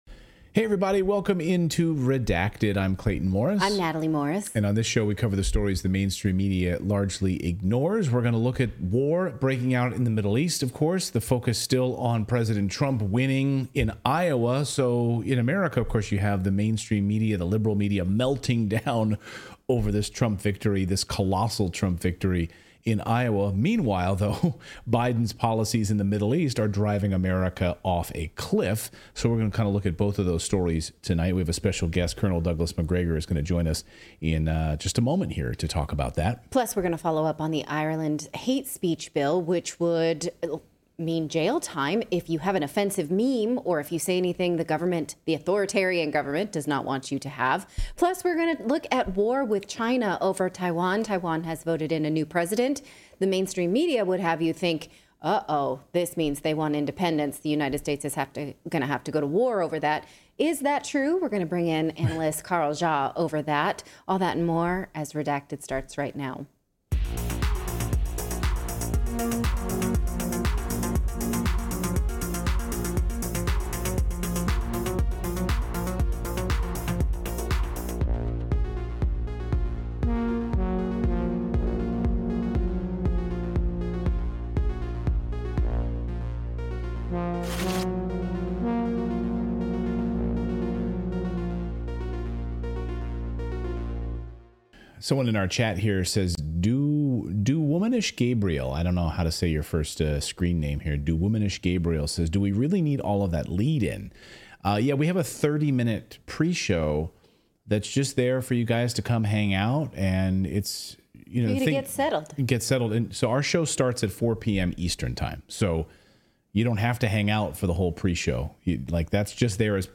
Col. Douglas MacGregor joins us live.